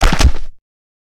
PixelPerfectionCE/assets/minecraft/sounds/mob/guardian/land_death.ogg at mc116
land_death.ogg